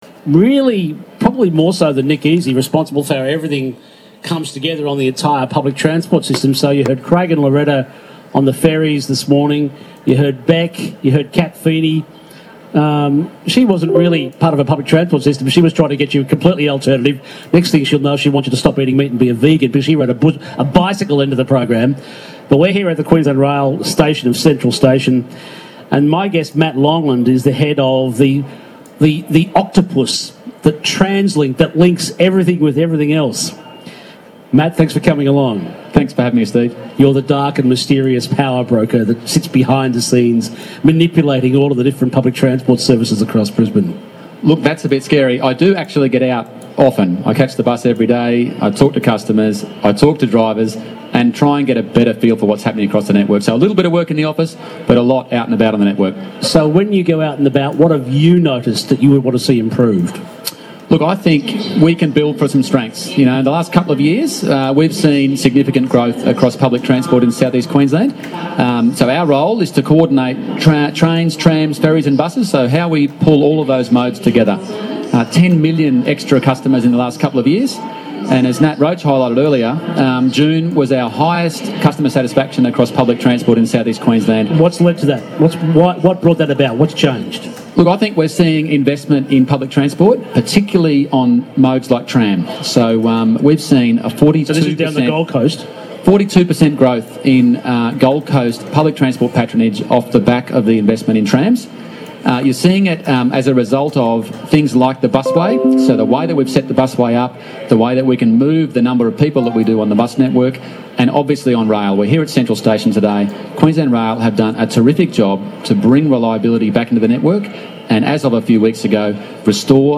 Interview on ABC Radio Brisbane 21st August 2019